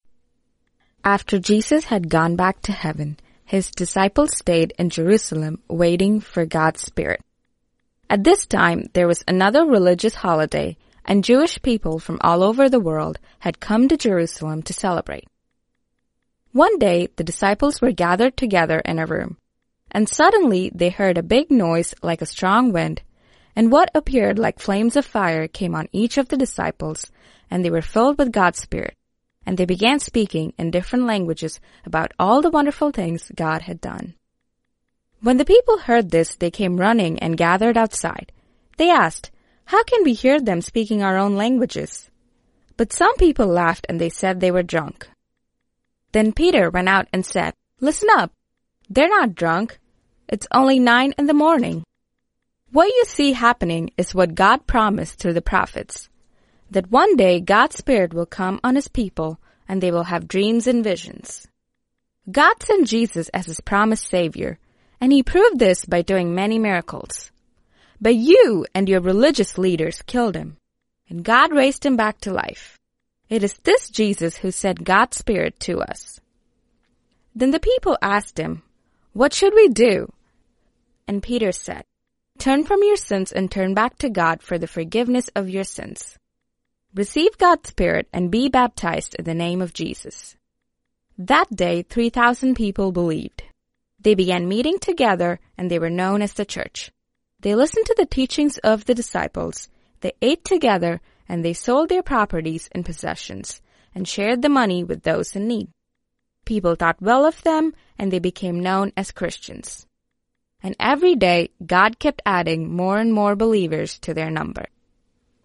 Deepen your intimacy with God by listening to an oral Bible story each day.